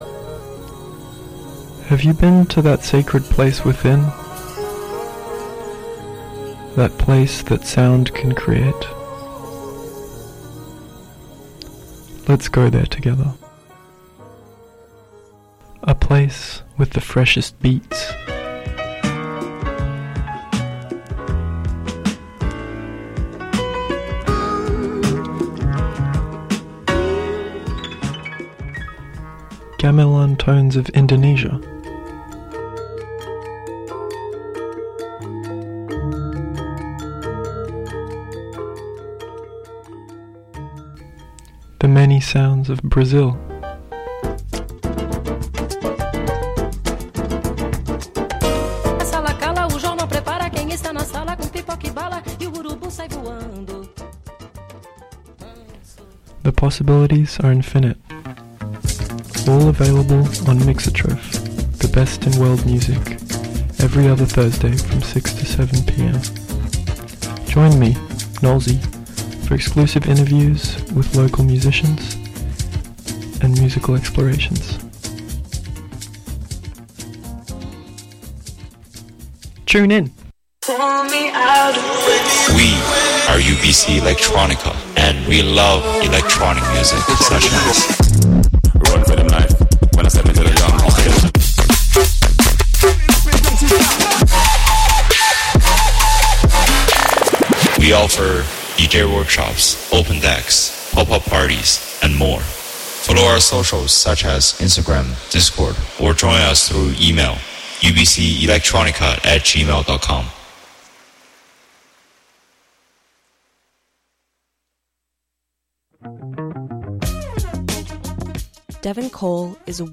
Played some of our favs by them + some Japanese influences on their music <3 GET CHICKENIZEDDDDDD